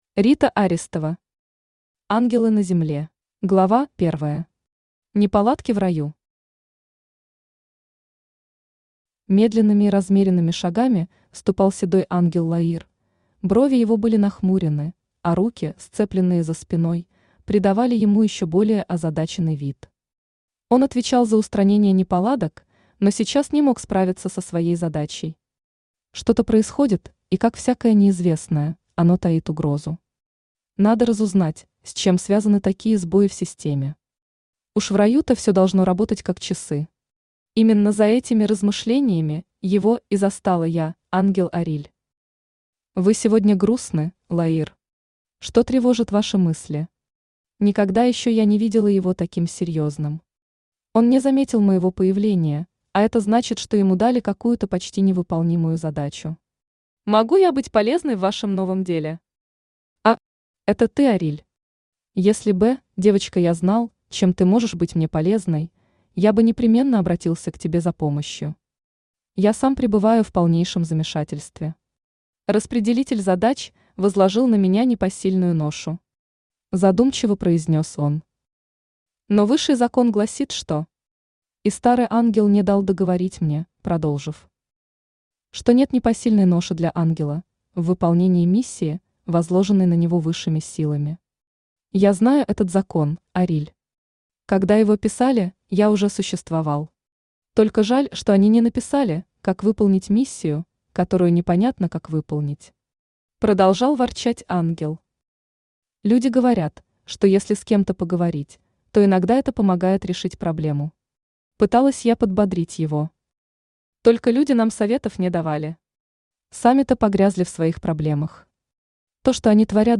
Аудиокнига Ангелы на Земле | Библиотека аудиокниг
Aудиокнига Ангелы на Земле Автор Рита Аристова Читает аудиокнигу Авточтец ЛитРес.